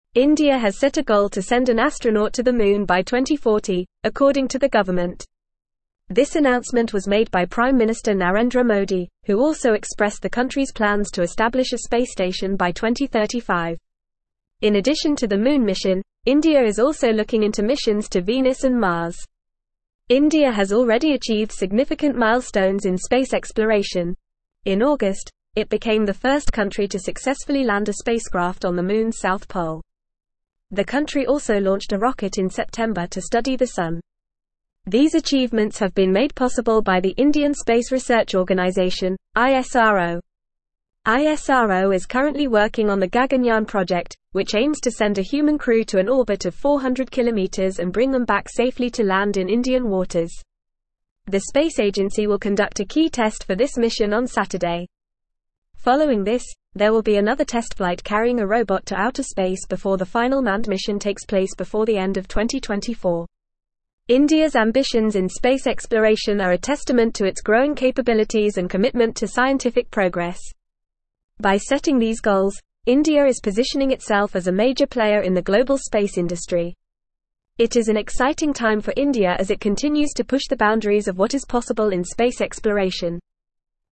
Fast
English-Newsroom-Advanced-FAST-Reading-Indias-Ambitious-Space-Goals-Moon-Mars-and-More.mp3